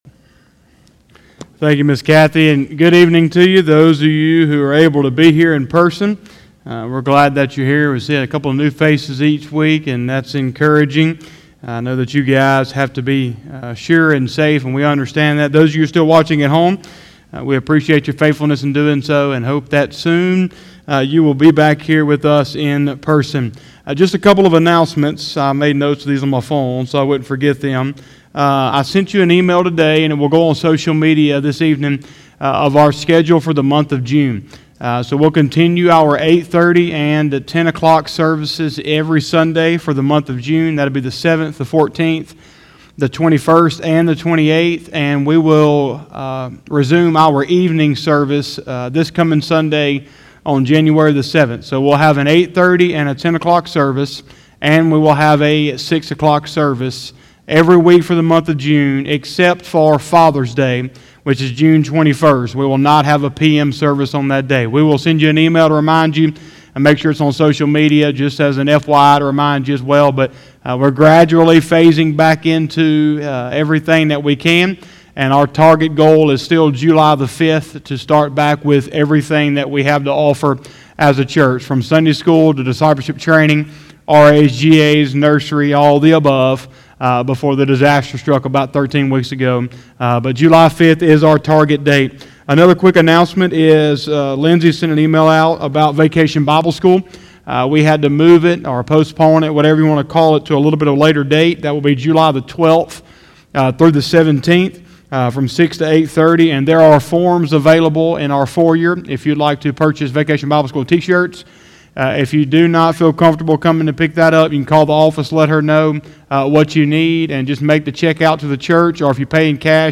06/03/2020 – Wednesday Evening Service